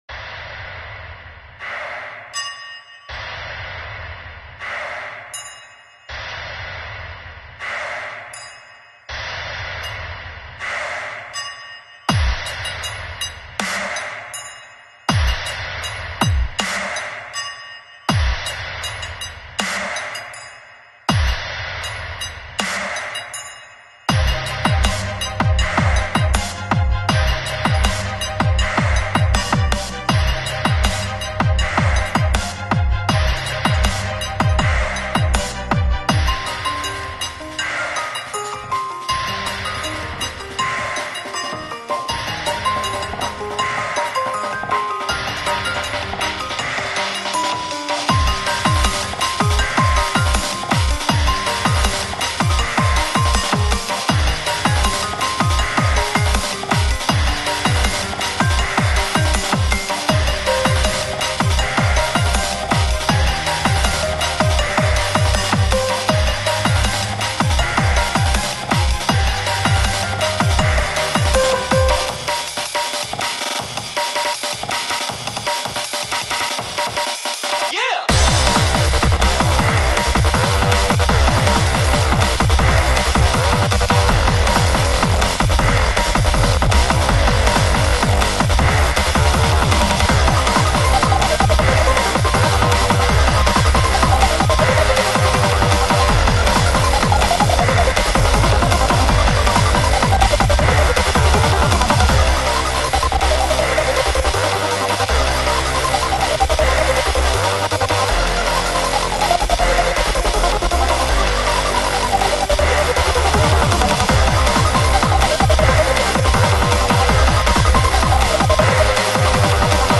DnB.
awesome reese bass